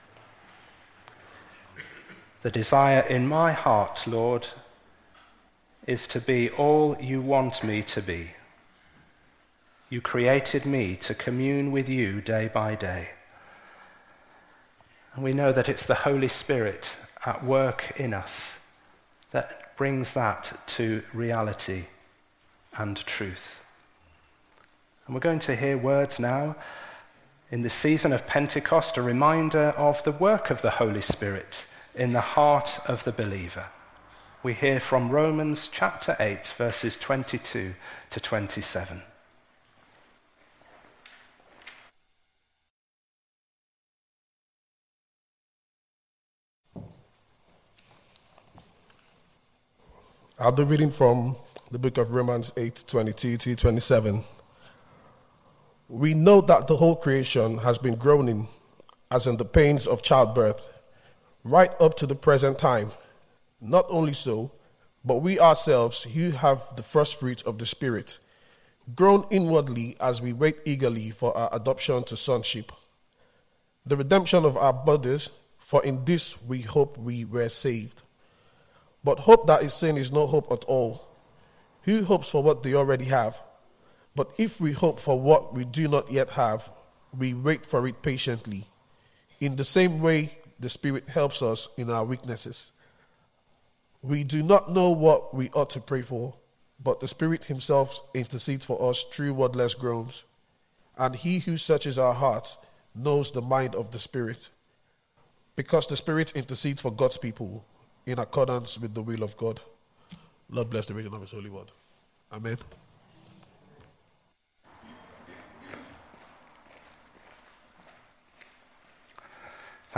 An audio version of the sermon is also available.
Service Type: Sunday Morning